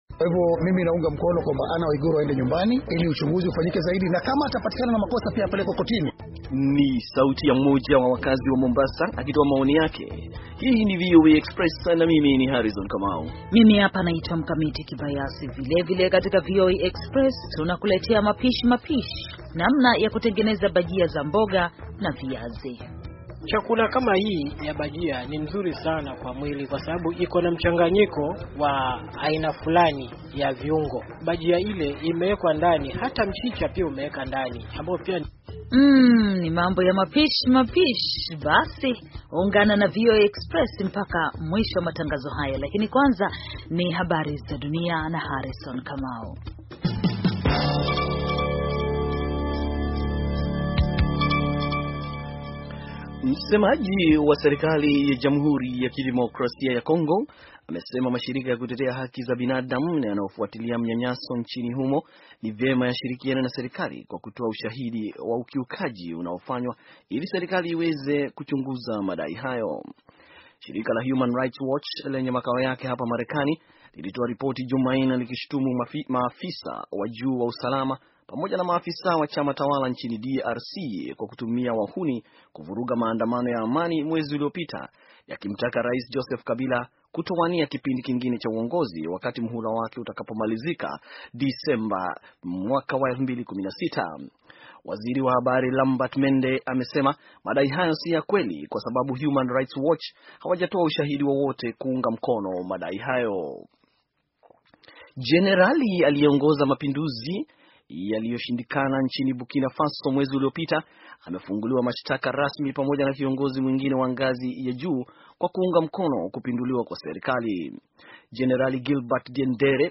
Taarifa ya habari - 6:03